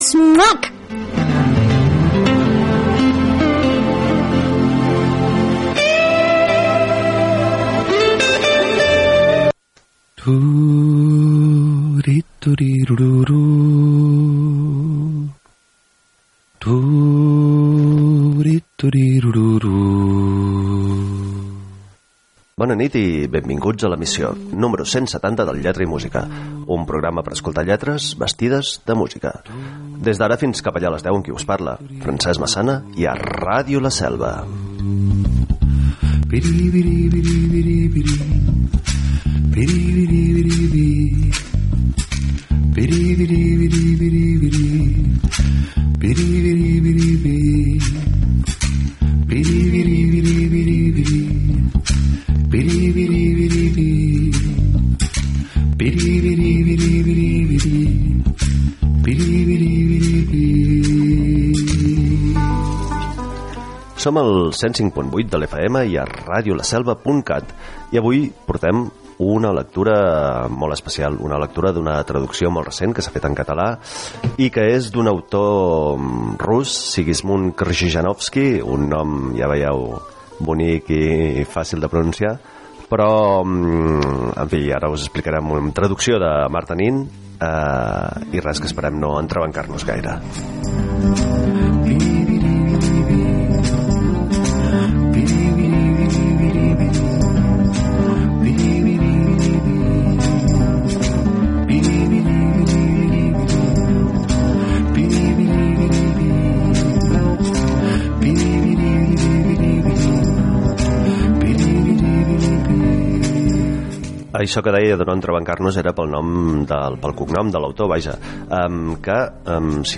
Un programa per escoltar lletres vestides de música. I per llegir textos nus. I per deixar-nos tapar amb músiques sense lletra.